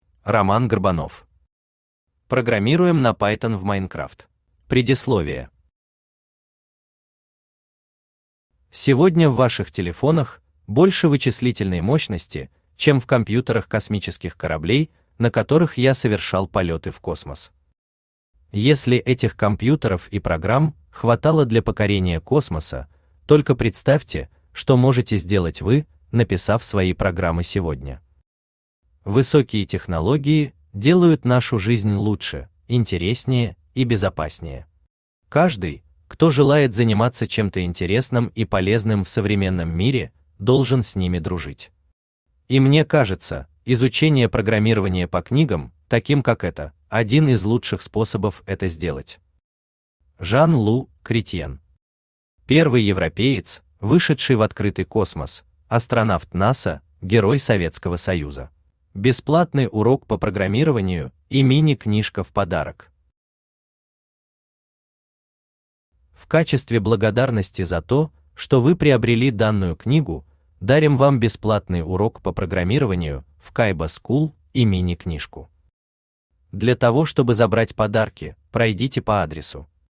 Аудиокнига Программируем на Python в Minecraft | Библиотека аудиокниг
Aудиокнига Программируем на Python в Minecraft Автор Roman Gurbanov Читает аудиокнигу Авточтец ЛитРес.